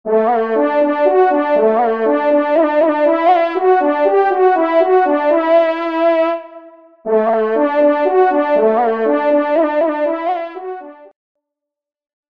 Genre : Fanfare d’Animaux